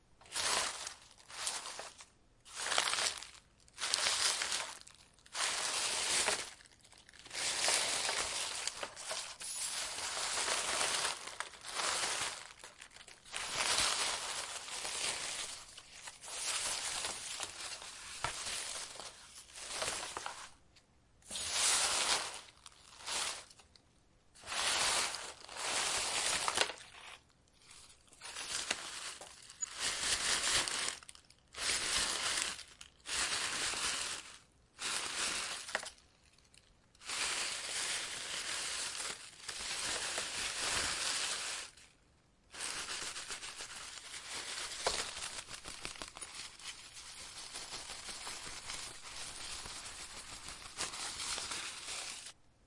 描述：灰树的夜记录与吹在风的叶子的。
Tag: 柔软 夜晚 背景声 白噪声 背景 树木 自然 现场记录 吹制 russeling 氛围 ATMOS 福雷斯特 声景 环境 夜景 氛围 灰树 气氛